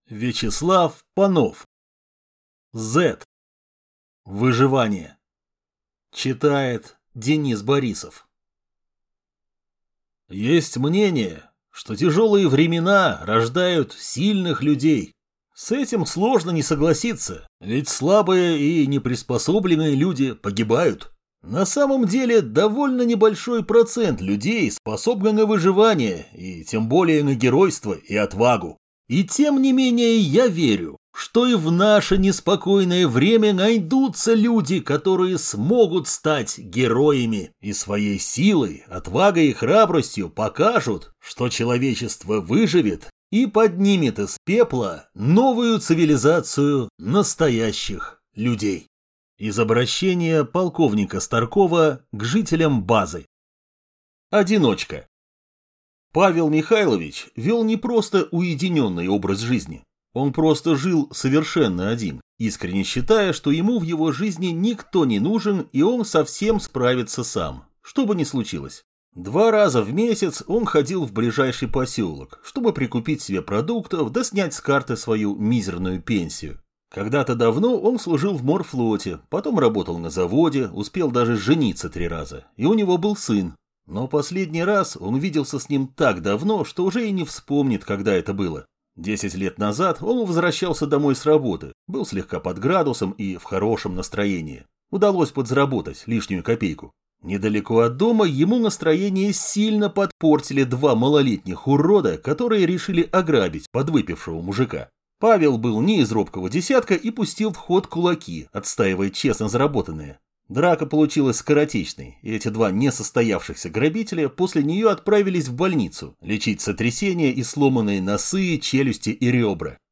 Аудиокнига Z. Выживание | Библиотека аудиокниг